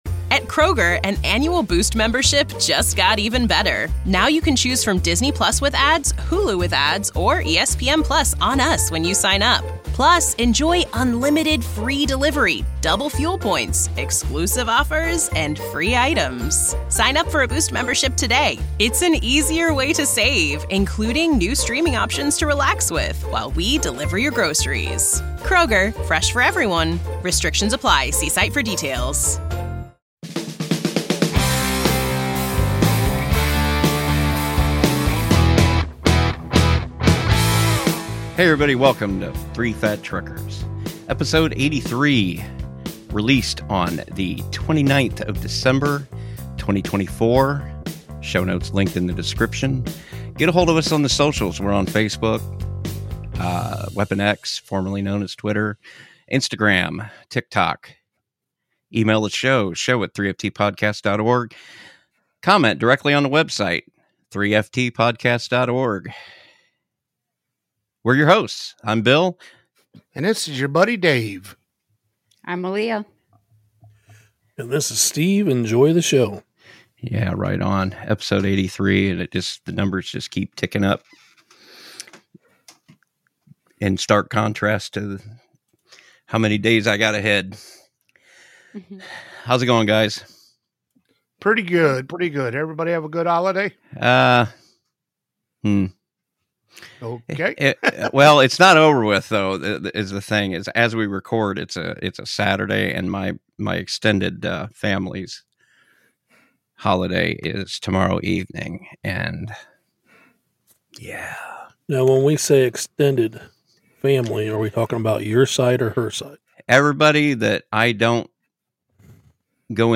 We just kick back and enjoy some good conversation among friends. We do get into an article about Ohio reopening some rest areas that have been down for construction for some time.